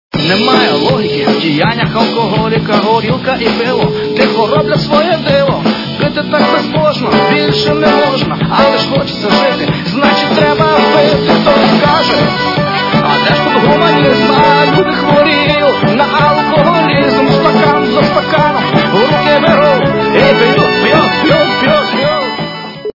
украинская эстрада